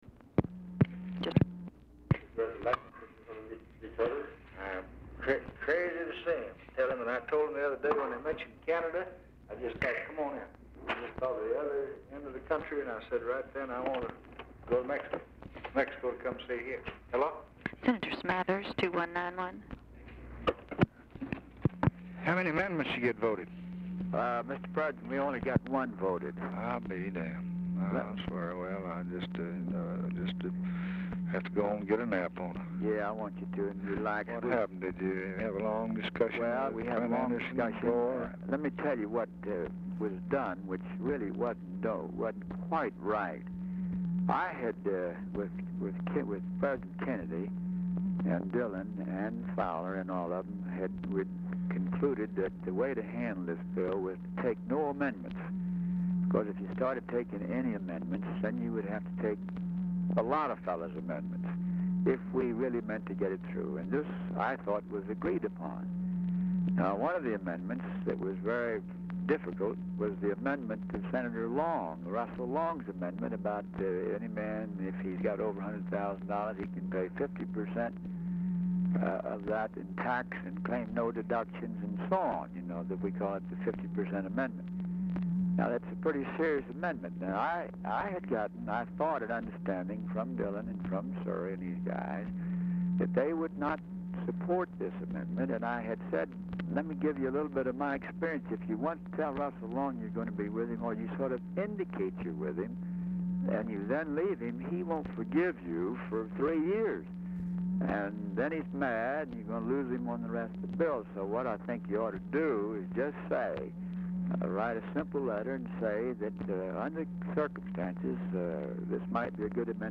Telephone conversation # 454, sound recording, LBJ and GEORGE SMATHERS, 12/12/1963, 3:20PM | Discover LBJ
OFFICE CONVERSATION ABOUT INVITING HEADS OF STATE OF CANADA AND MEXICO FOR US VISIT PRECEDES CALL
Format Dictation belt
Specific Item Type Telephone conversation